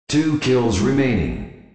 TwoKillsRemaining.wav